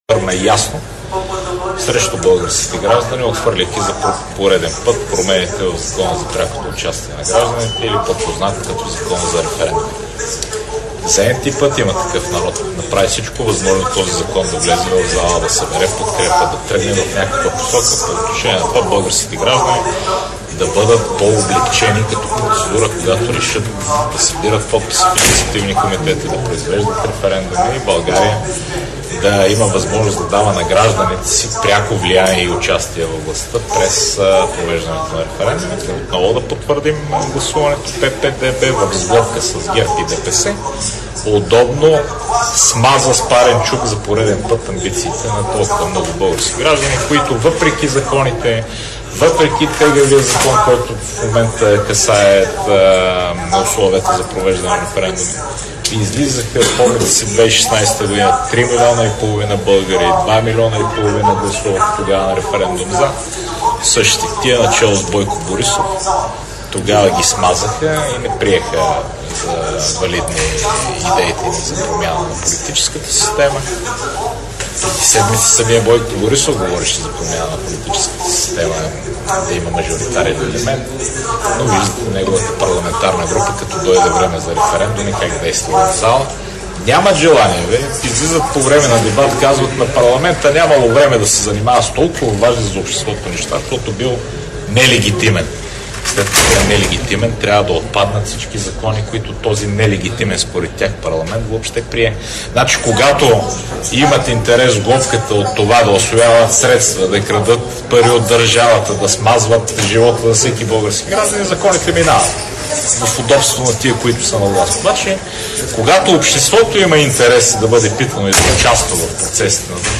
11.40 - Брифинг на Борислав Гуцанов от БСП за България.  - директно от мястото на събитието (Народното събрание)